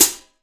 Index of /90_sSampleCDs/AKAI S6000 CD-ROM - Volume 3/Hi-Hat/14INCH_FLANGE_HI_HAT